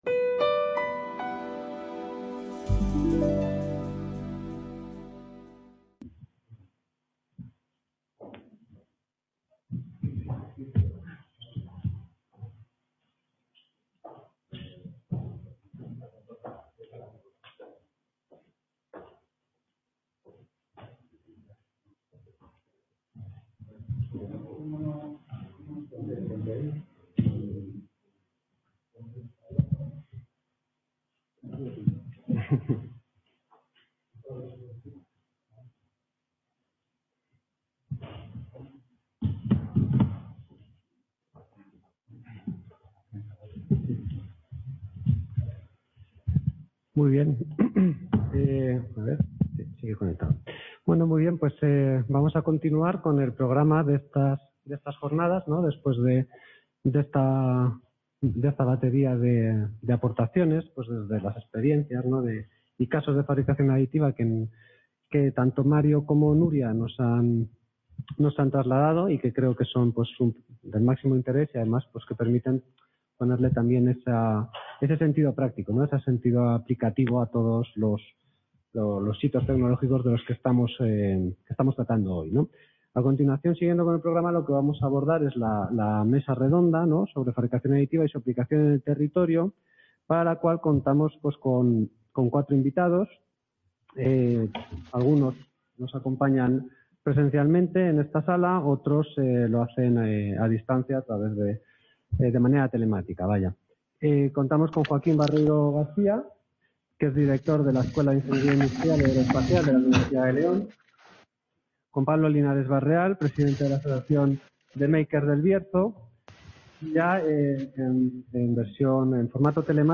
Mesa Redonda sobre fabricación aditiva y su aplicación en el territorio (Varios ponentes)
El Centro Asociado a la UNED de Ponferrada inaugura oficialmente el Curso Académico 2020/2021 con una jornada sobre la Fabricación Aditiva.